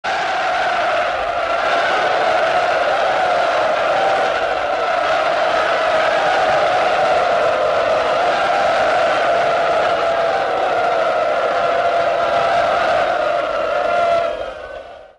Звуки кричащей толпы
Звук: яростный рев толпы, жаждущей схватки